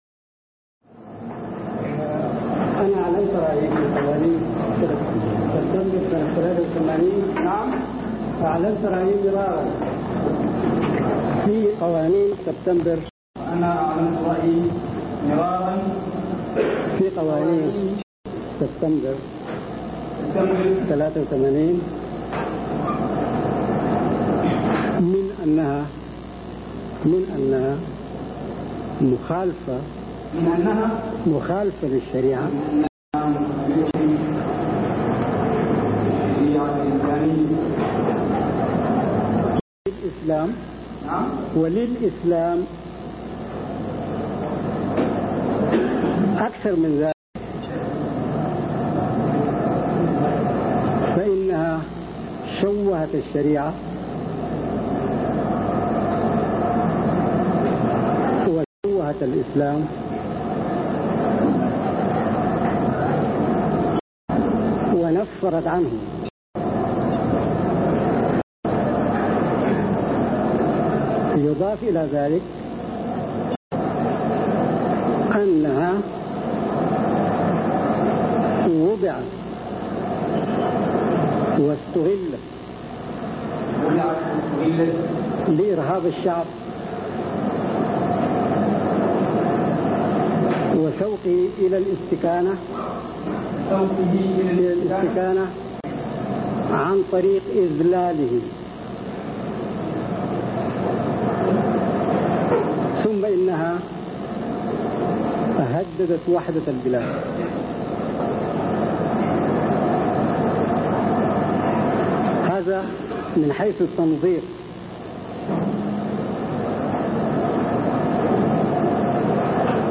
كلمة الأستاذ محمود على محكمة المهلاوى
يمكن السماع للكلمة بصوت الأستاذ بالنقر على زر التشغيل أدناه: